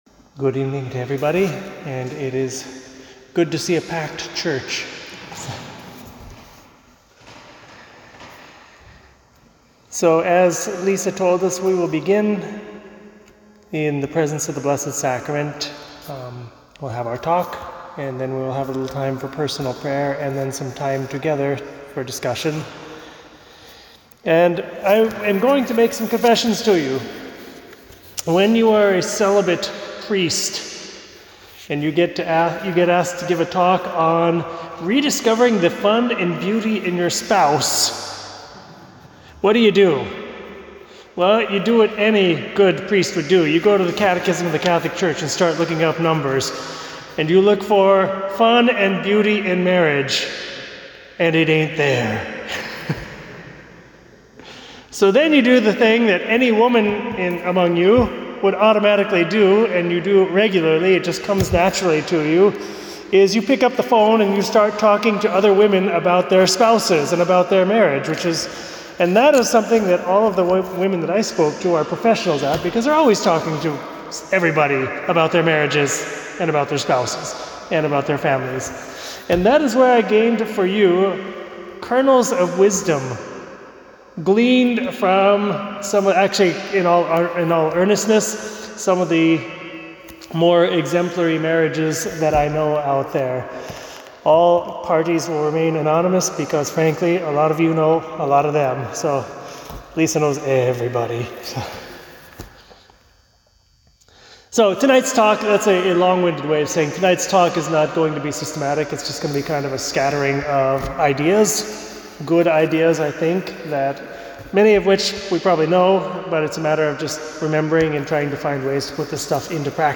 Marriage Evening Talk and Meditation - RC NY Tri-State